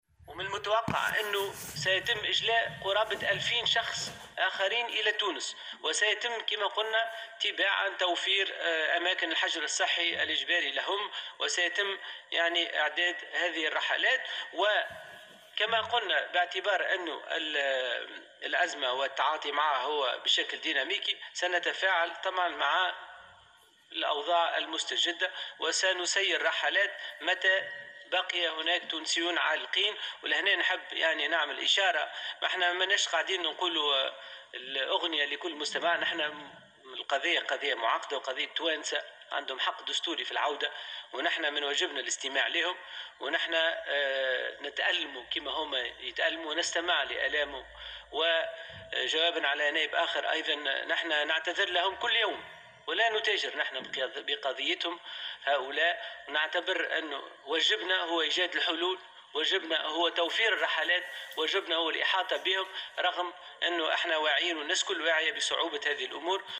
وأضاف على هامش جلسة مساءلة في البرلمان حول التونسيين العالقين في الخارج، أنه سيتم توفير مراكز للحجر الصحي الإجباري لهم، مشيرا إلى أن عمليات الإجلاء تعتبر معقدة وصعبة نظرا للتدابير الوقائية التي اتخذتها الدول في مواجهة انتشار فيروس كورونا.